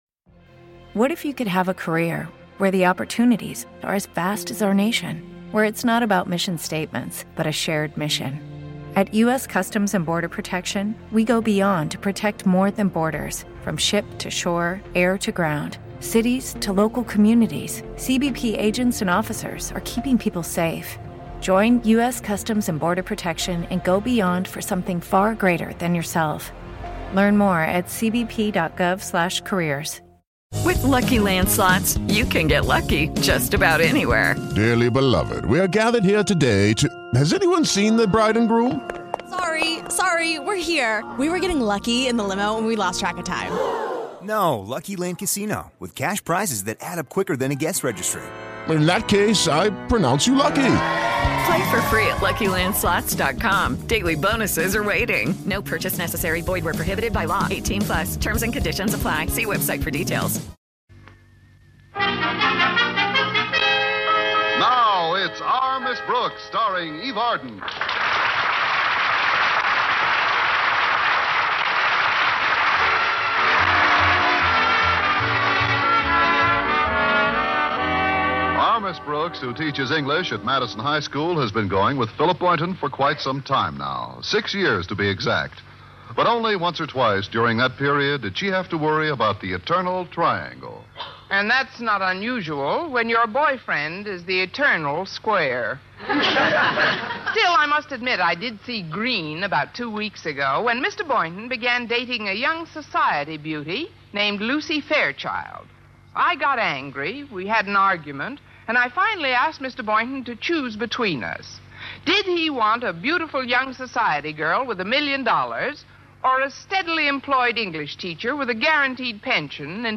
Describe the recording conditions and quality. Our Miss Brooks was a beloved American sitcom that ran on CBS radio from 1948 to 1957.